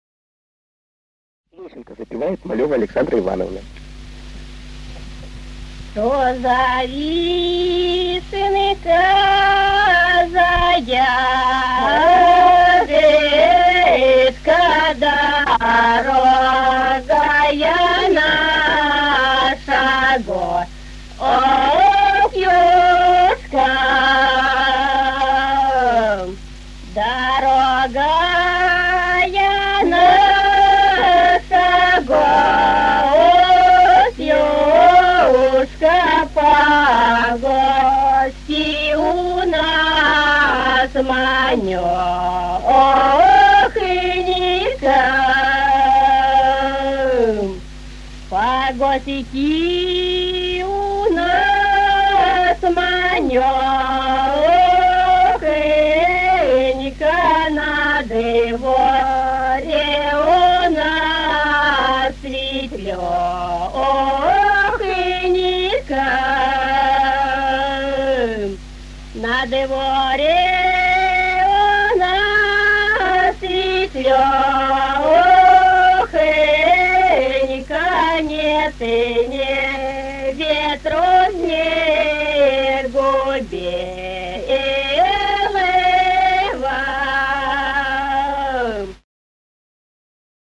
Русские народные песни Владимирской области 19. Что за вишенка, за ягодка (свадебная жениху) с. Мошок Судогодского района Владимирской области.